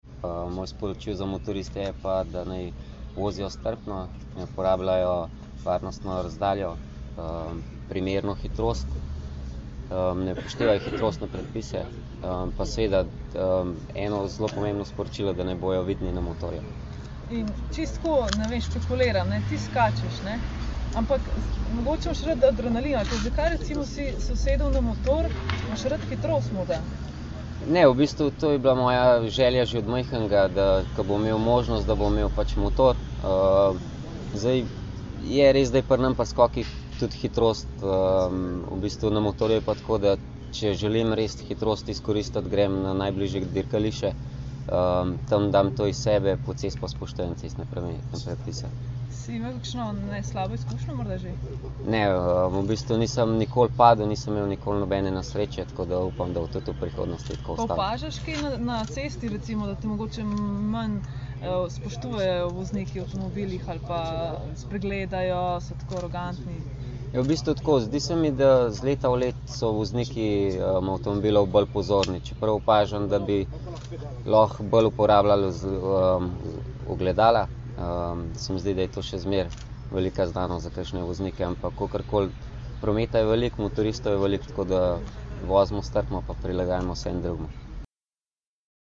Skupaj smo jih zbrali danes, 13. junija 2011, v Policijski akademiji v Tacnu, kjer smo organizirali novinarsko konferenco.